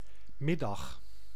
Ääntäminen
Synonyymit noen Ääntäminen : IPA: [mɪ.daɣ] Tuntematon aksentti: IPA: /ˈmɪ.dɑx/ Haettu sana löytyi näillä lähdekielillä: hollanti Käännös 1. pusdiena {f} 2. dienas vidus {m} Suku: m .